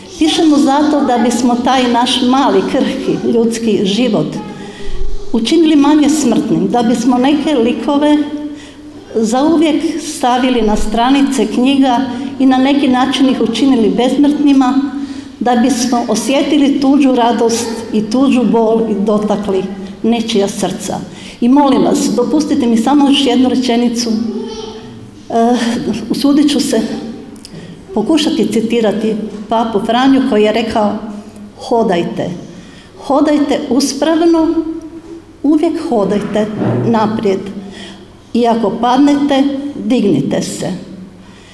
Obilježba je zaključena prigodnim programom u Društvenom domu i turističko-informativnom centru u Lekeniku, gdje su načelnik Ivica Perović i sada već bivši predsjednik Općinskog vijeća Marin Čačić dodijelili javna priznanja Općine Lekenik u 2025. godini.